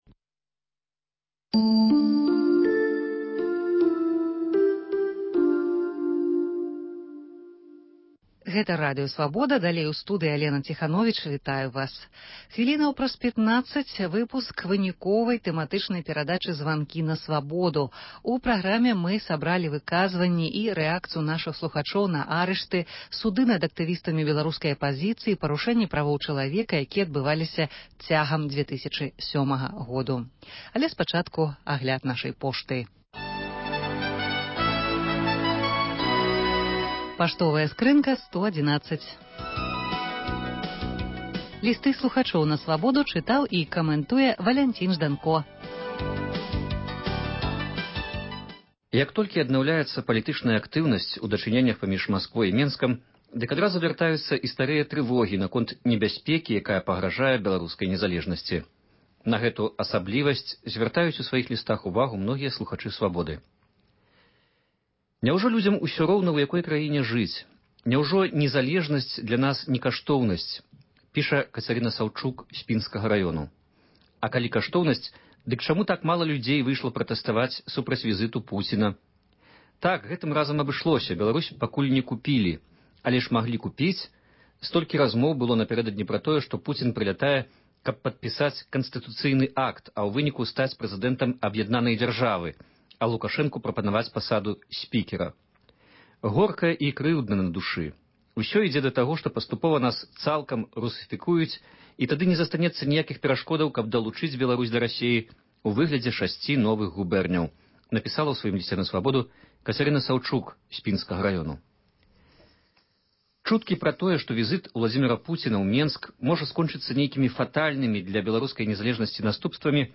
Званкі на Свабоду